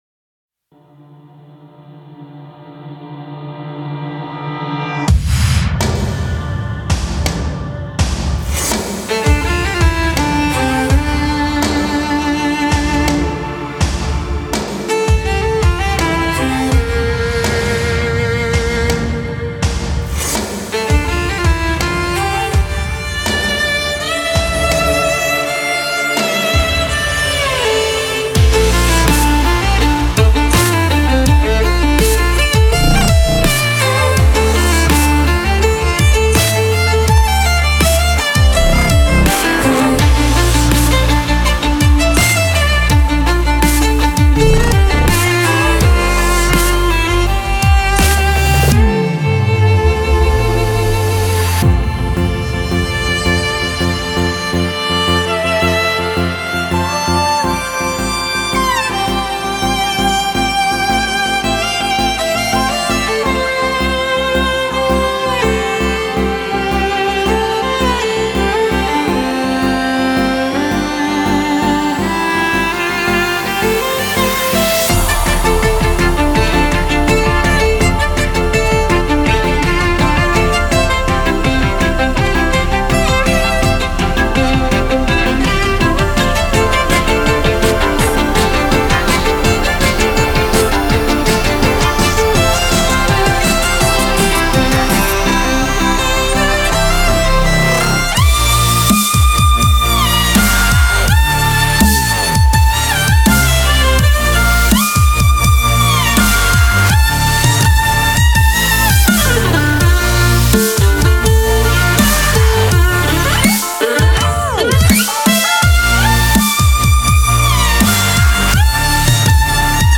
Категория: танцевальная